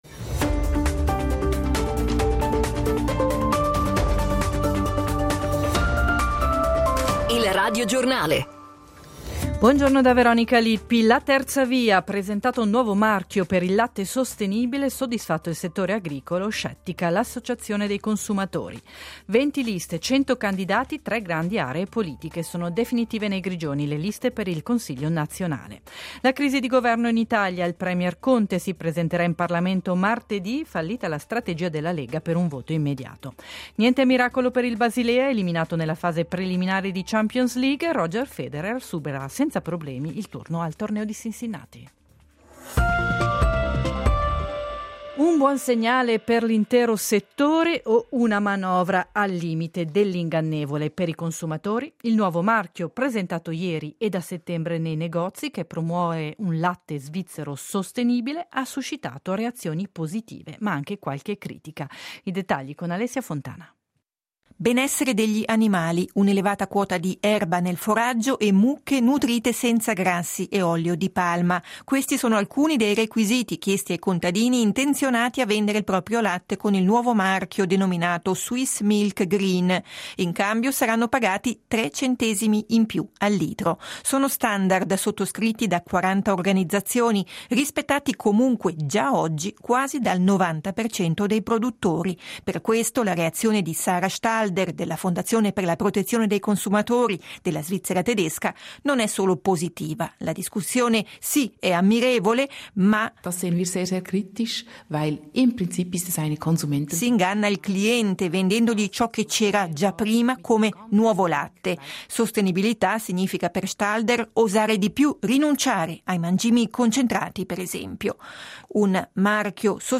Radiogiornale